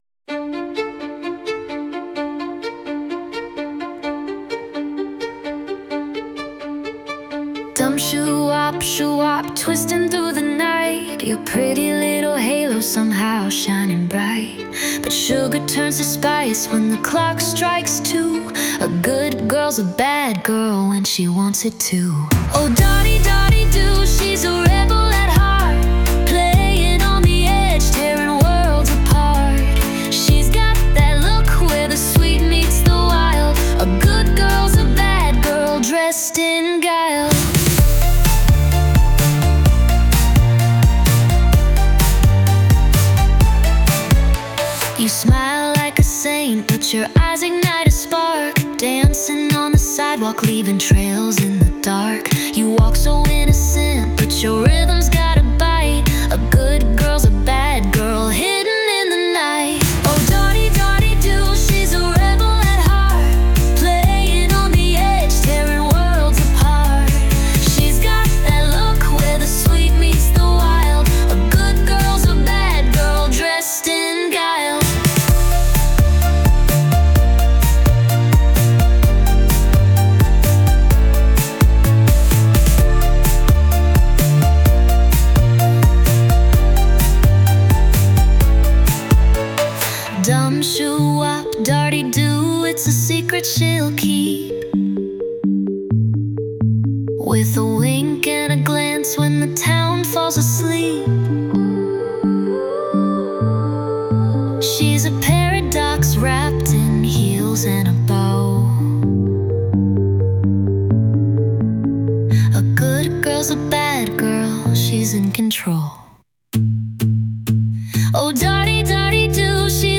Ethereal